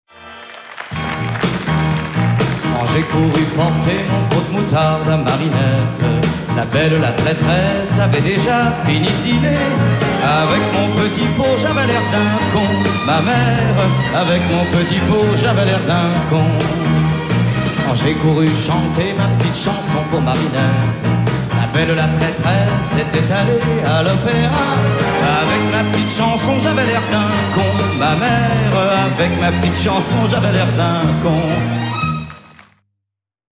INEDITS SOLO TV/RADIO